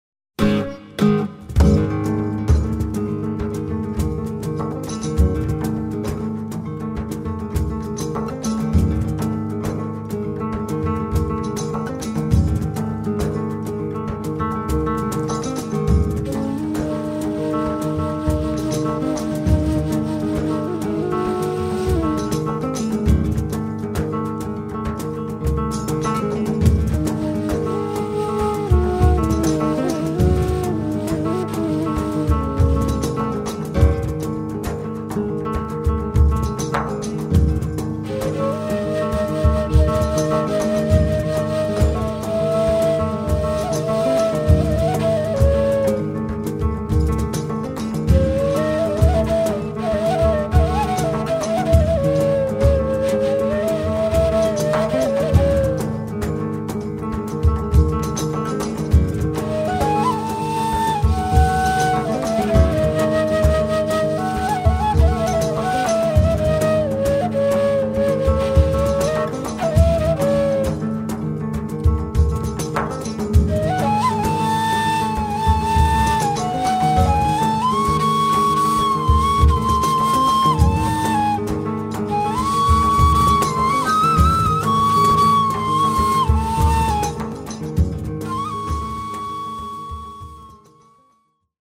kitara
cajon, darabukka, hang, shakers, dunun
obročni bobni, majhna zvočila
kalimba, hang, rig, darabukka, tapan, glinen boben
kaval, gajde, tambura, klarinet
djembe, darabukka, tapan
klavir, harmonika